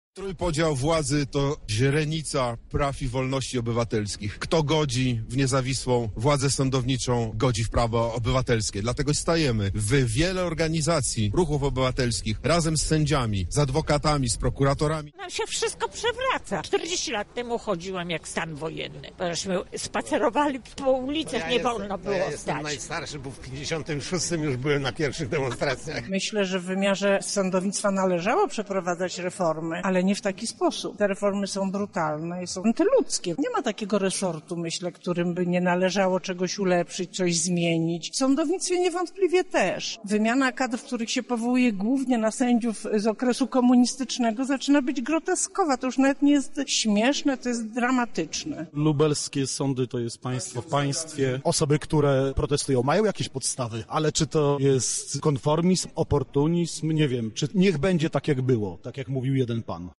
Na miejscu był nasz reporter:
relacja manifestacja